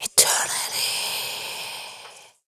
WHISPER 07.wav